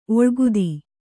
♪ oḷgudi